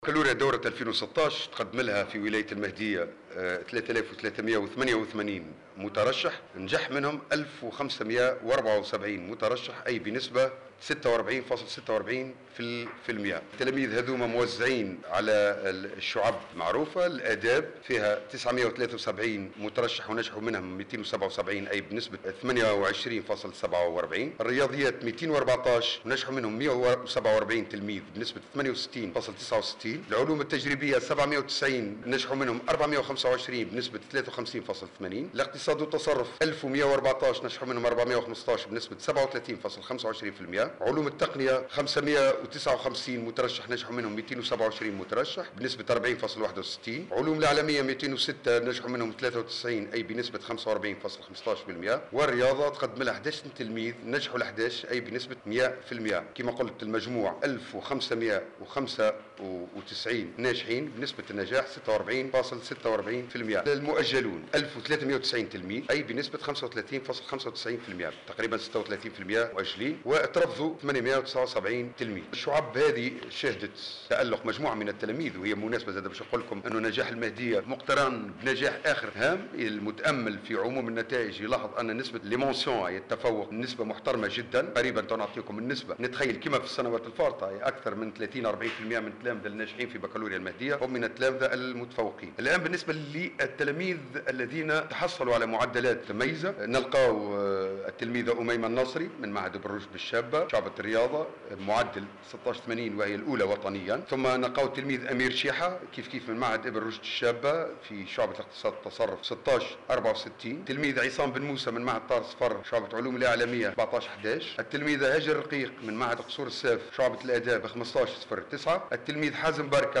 أعلن المندوب الجهوي للتربية بولاية المهدية منجي منصر في تصريح للجوهرة أف أم خلال ندوة صحفية عقدت اليوم السبت 18 جوان 2016 أن نسبة النجاح في الدورة الرئيسية لامتحان الباكالوريا بالمهدية بلغت 46,46% وهو ما جعلها تحتل المرتبة الرابعة على مستوى ولايات الجمهورية والمرتبة الخامسة على مستوى المندوبيات.